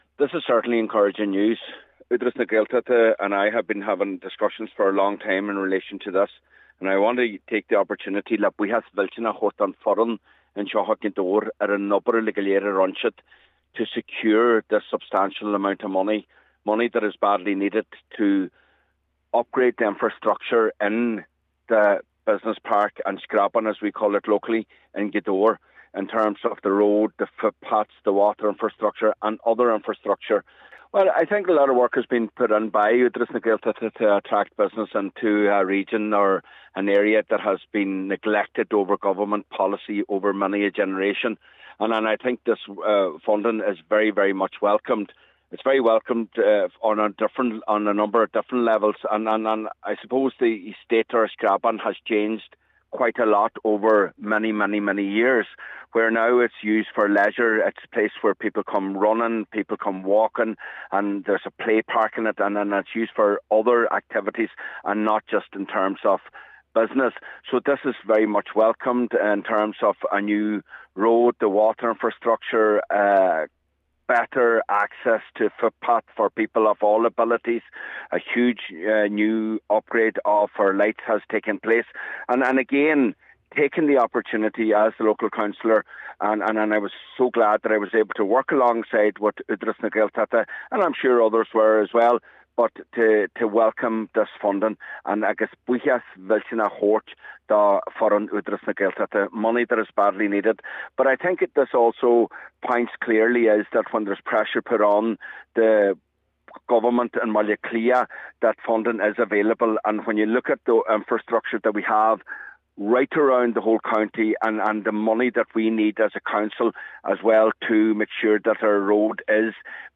The news has been welcomed by Cllr Micheal Choilm Mac Giolla Easbuig but says more still needs to be done to revitalise Gaeltacht areas….